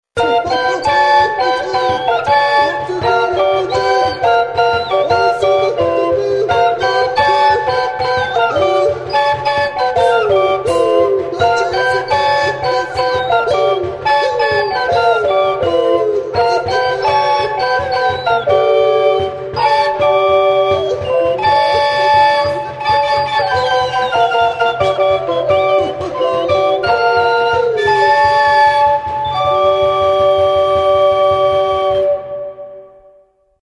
Aerophones -> Flutes -> Fipple flutes (two-handed) + kena